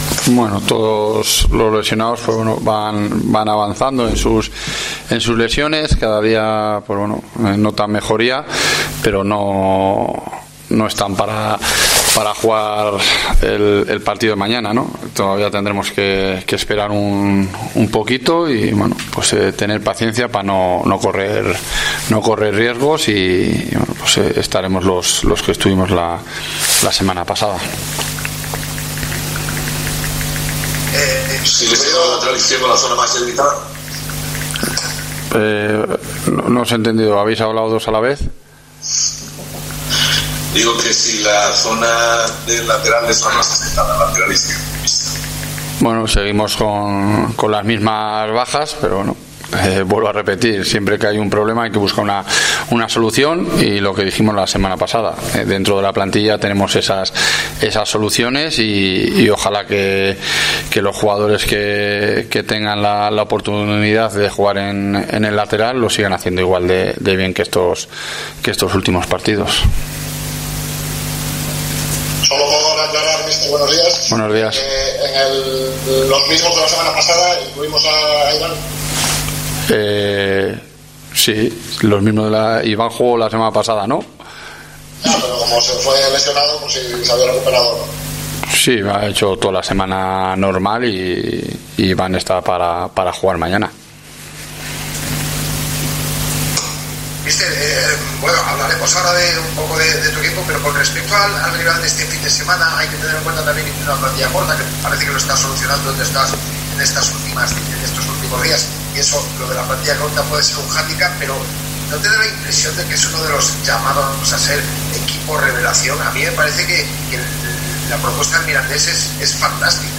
AUDIO: Escucha aquí al entrenador de la Ponferradina en la previa de la cuarta jornada de LaLiga SmartBank donde los blanquiazules jugarán este...